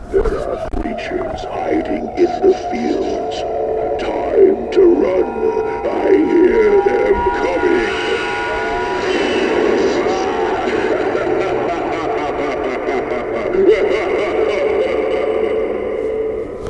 🔊 i heard this ghoul adressing me.